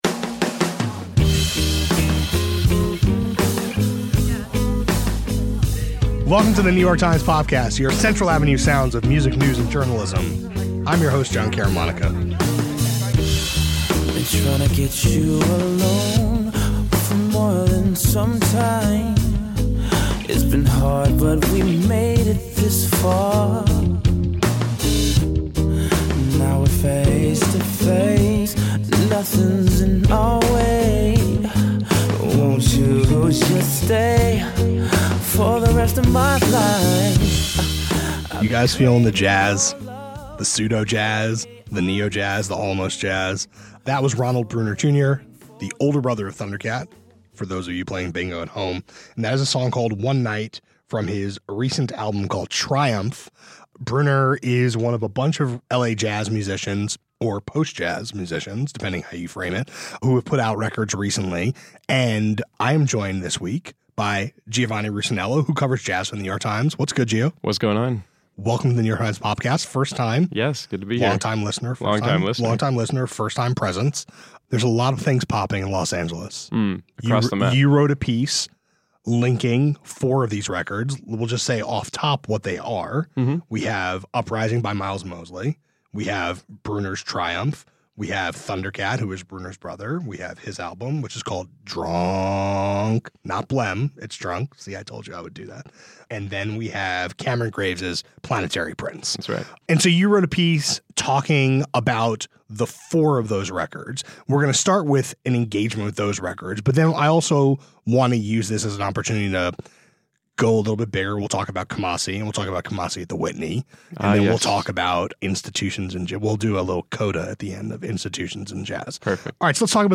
Kamasi Washington and some Los Angeles musicians are producing vital recordings, but N.E.A. funding is uncertain. A conversation about the state of jazz.